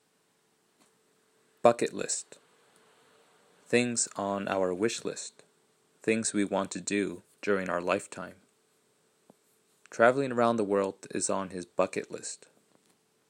英語ネイティブによる発音は下記のリンクをクリックしてください。
bucketlist.mp3